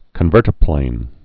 (kən-vûrtə-plān)